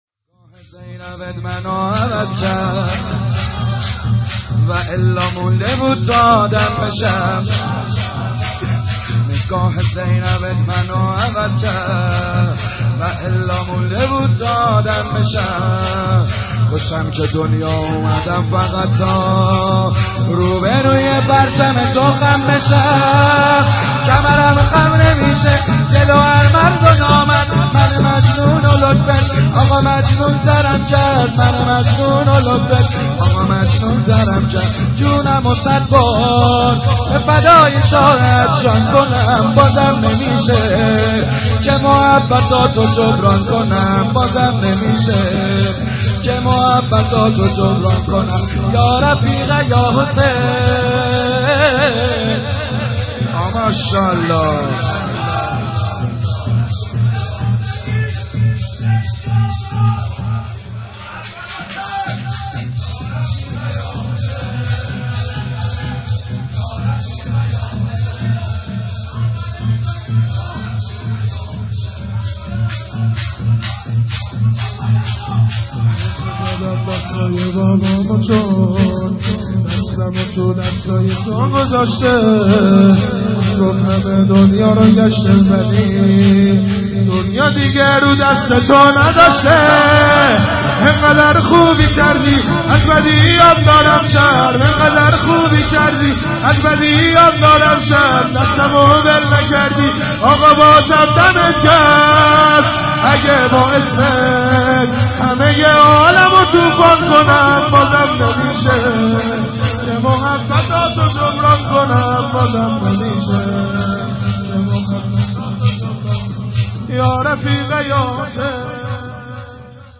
شور - نگاه زینبت منو عوض کرد